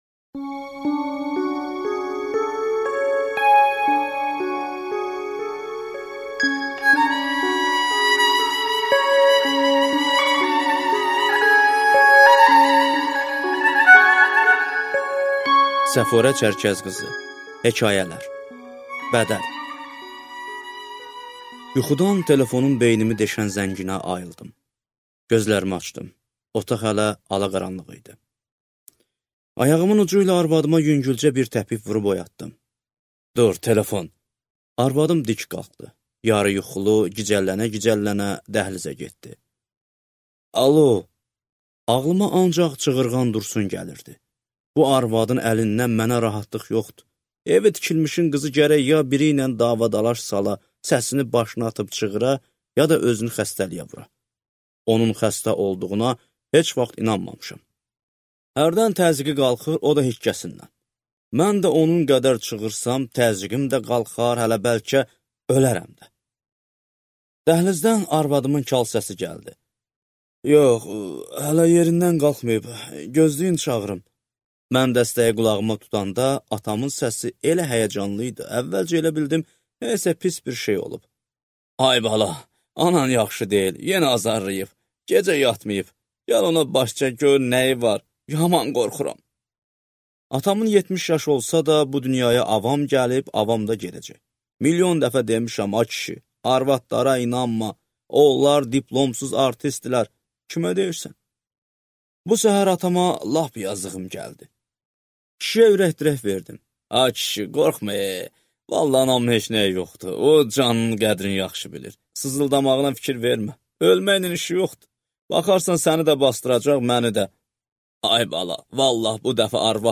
Аудиокнига Hekayələr | Библиотека аудиокниг
Aудиокнига Hekayələr